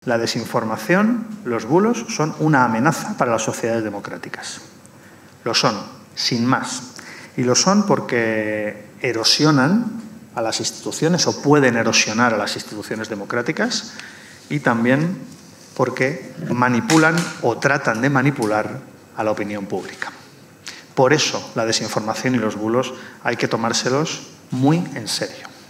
En su intervención, el ministro Bolaños apeló a “lo necesario que es hacer este tipo de estudios” ya que, en su opinión, “la desinformación y los bulos son